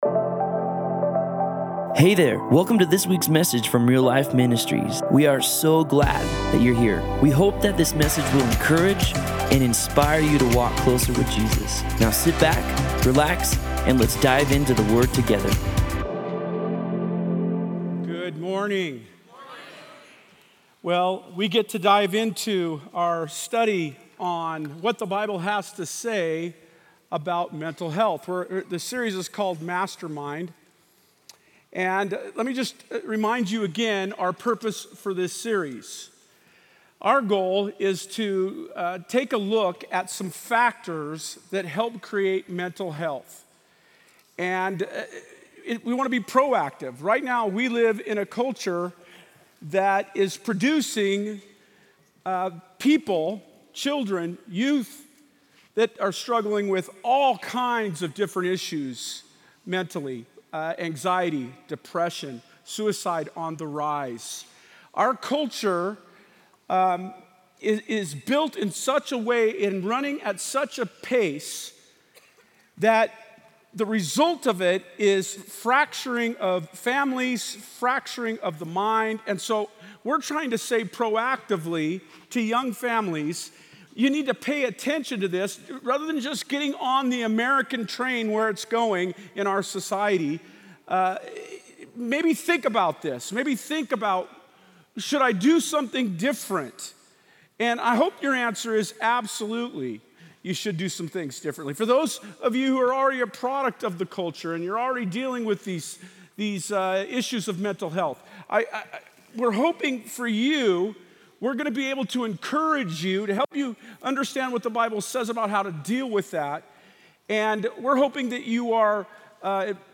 Other Sermon in this Series